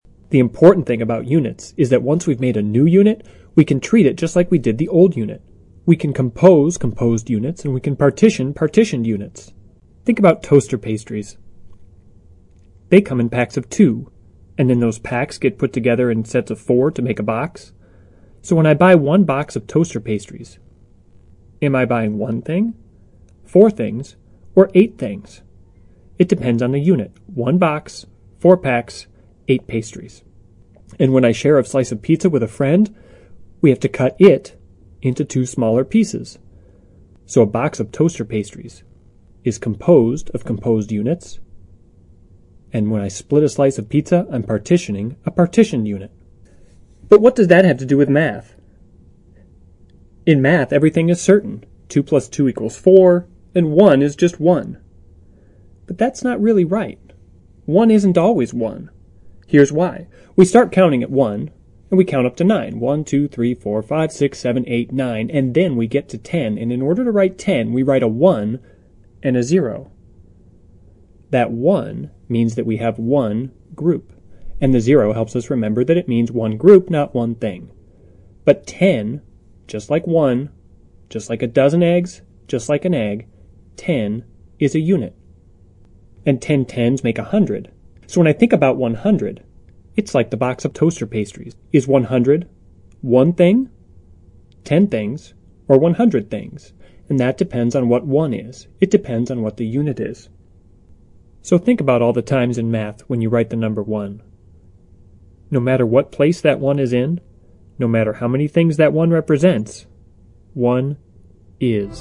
TED演讲:一就是一吗(2) 听力文件下载—在线英语听力室